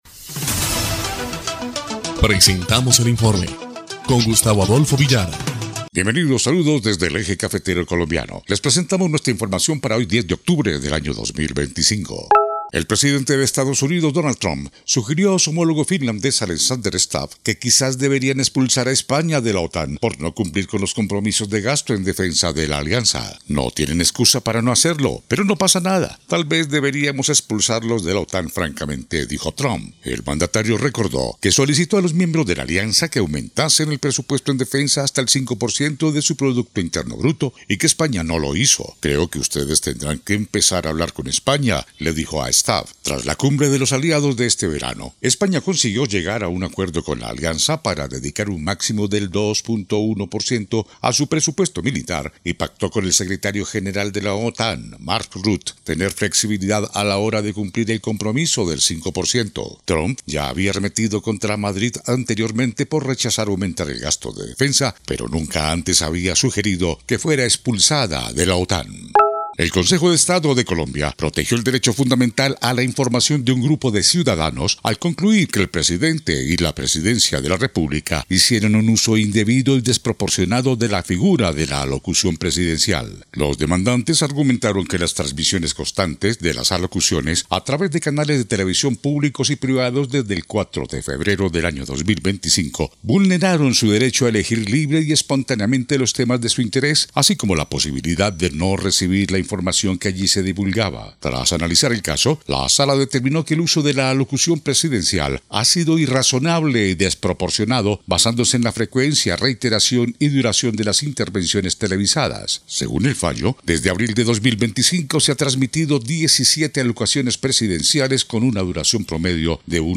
EL INFORME 3° Clip de Noticias del 10 de octubre de 2025